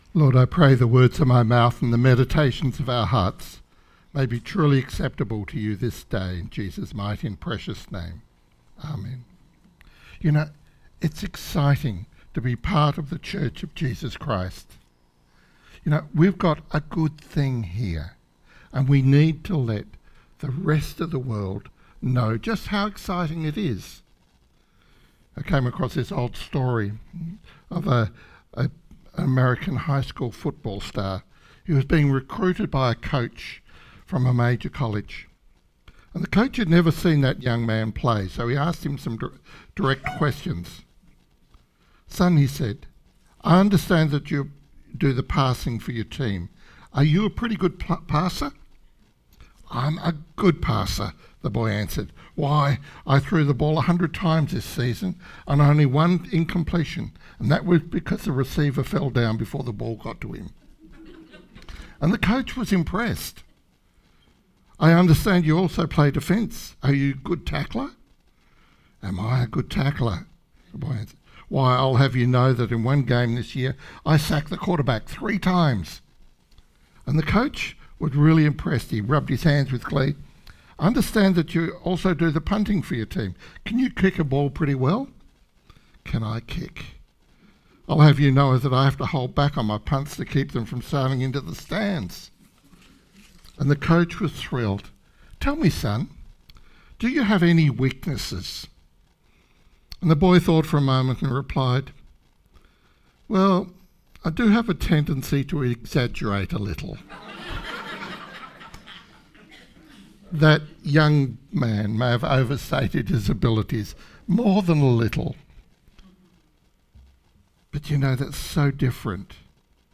Sermon 12th May – A Lighthouse to the community